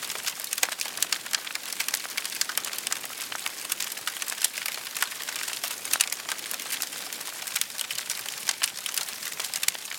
SFX_FireSmall_L.wav